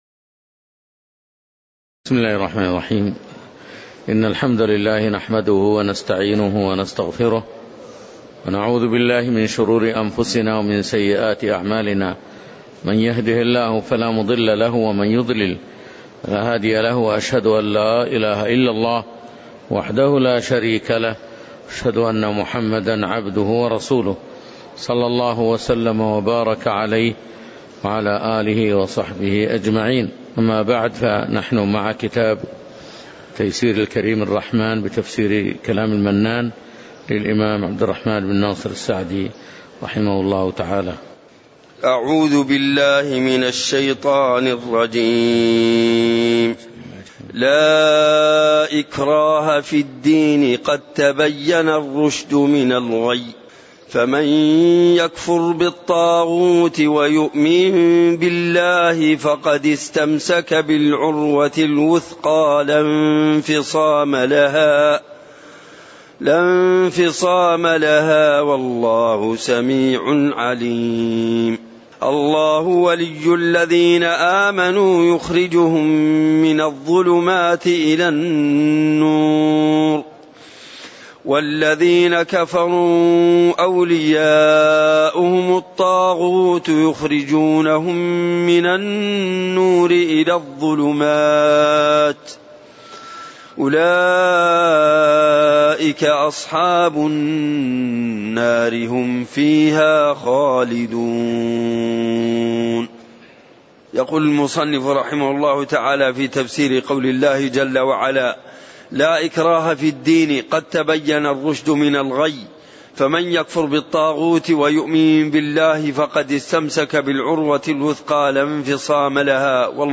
تاريخ النشر ٧ جمادى الآخرة ١٤٣٩ هـ المكان: المسجد النبوي الشيخ